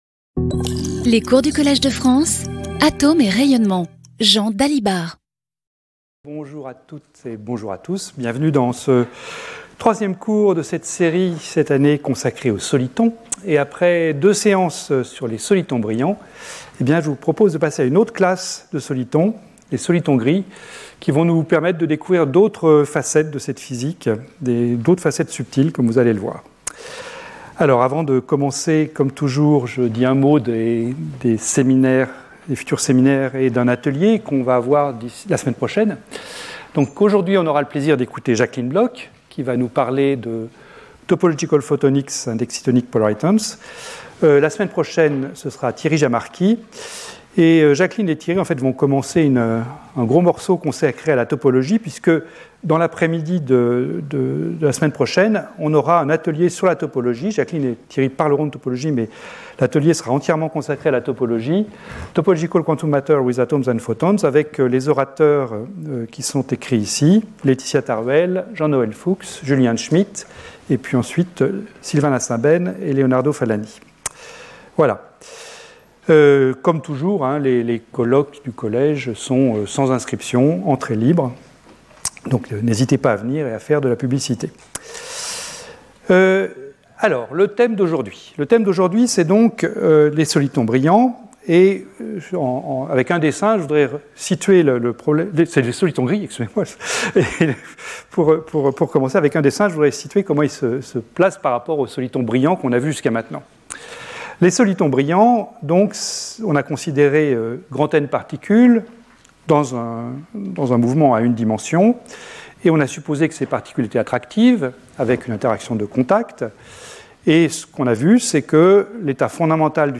Jean Dalibard Professeur du Collège de France
Cours